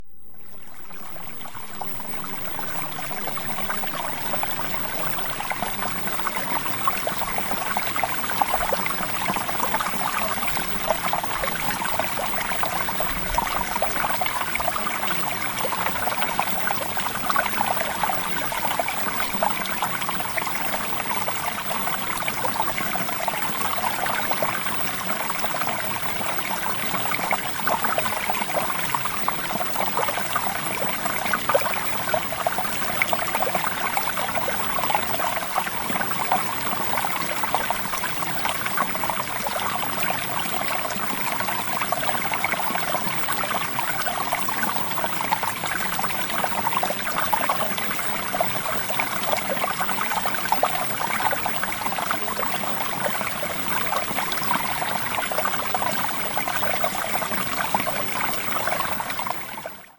SEDUCTIVE-MAGNETISM-Water-Sample.mp3